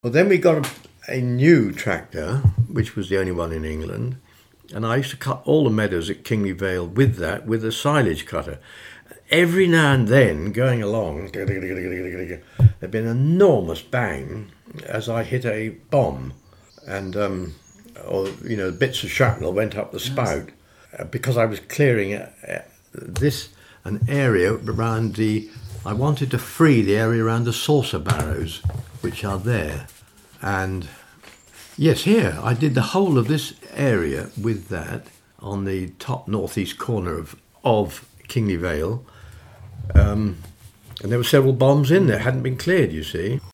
Oral history reveals fascinating story of Kingley Vale